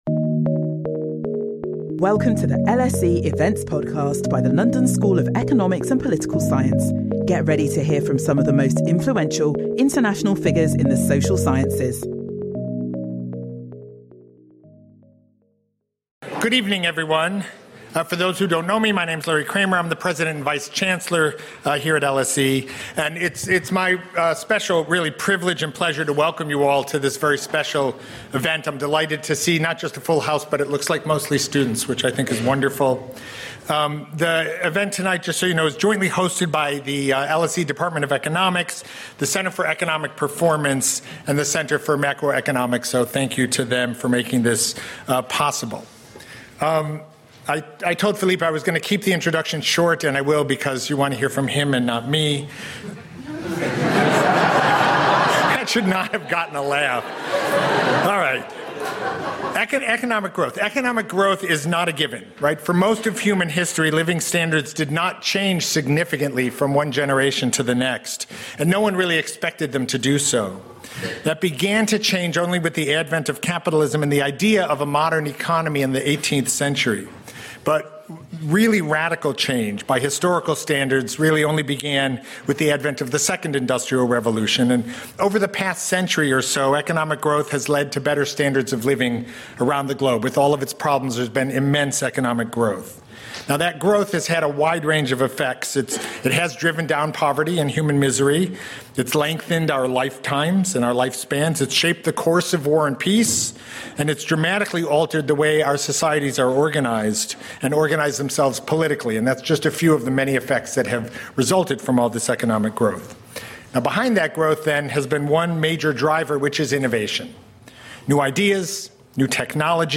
Join us for this special event with LSE's Philippe Aghion, joint recipient of the 2025 Nobel Memorial Prize in Economic Sciences.
This lecture will show how the Schumpeterian growth paradigm can shed light on recent history debates (secular stagnation, middle-income trap, European decline, impacts of AI) and be used to rethink capitalism.